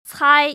[cāi]